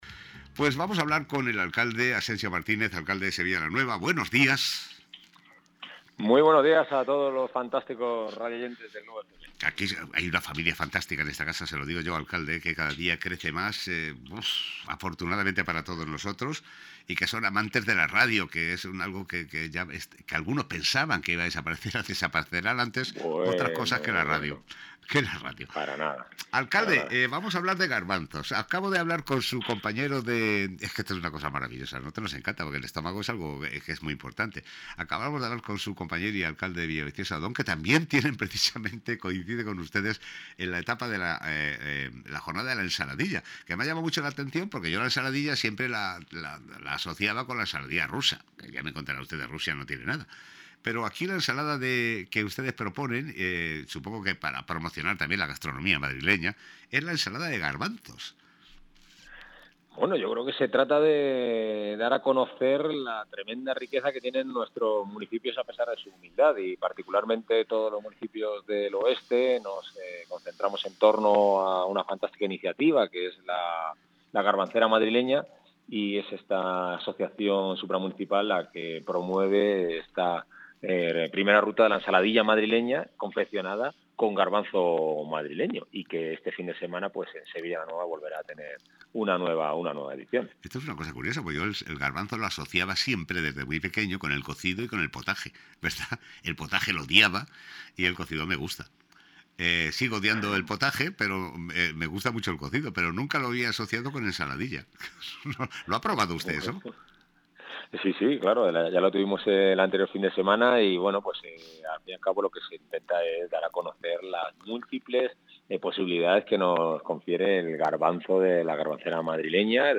Entrevista a Asensio Martínez, Alcalde de Sevilla la Nueva
Esta mañana el Alcalde de Sevilla la Nueva, Asensio Martínez, ha realizado una intervención en el programa de «La Portada» para hablar sobre las jornadas que van a realizar en su municipio sobre la ensalada de garbanzos.